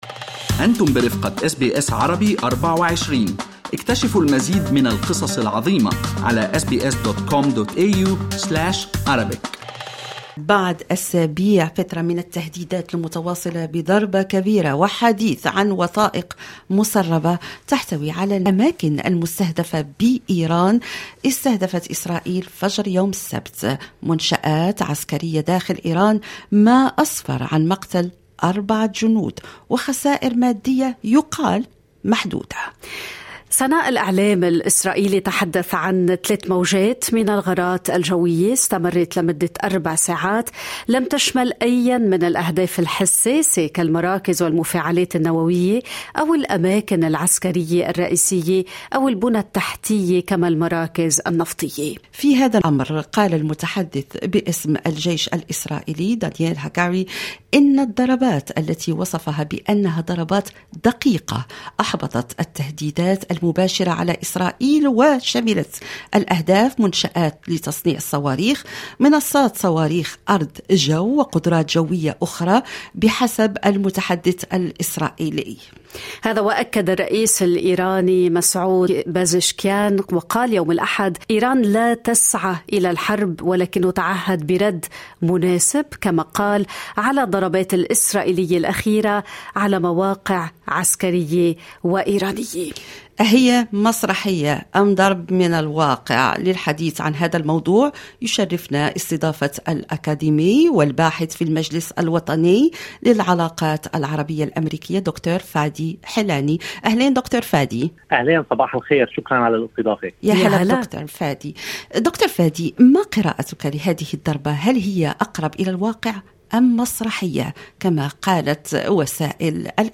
الولايات المتحدة قادرة لكنها غيرراغبة بممارسة الضغوط على حكومة نتانياهو: محلل سياسي أمريكي يشرح